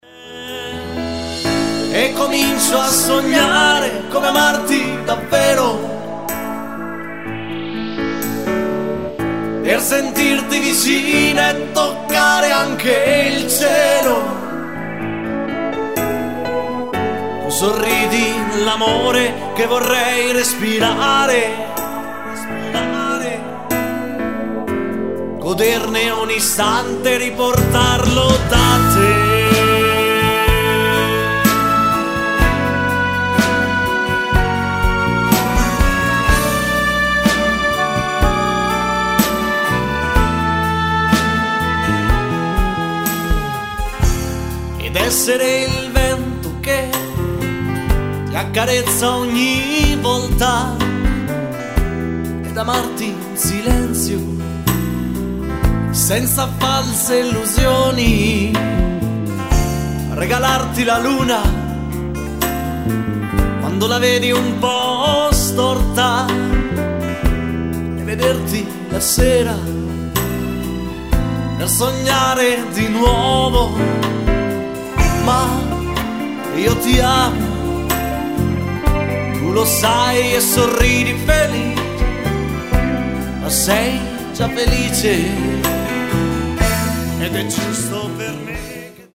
Moderato lento
Uomo